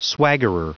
Prononciation du mot swaggerer en anglais (fichier audio)
Prononciation du mot : swaggerer